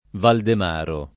[ valdem # ro ]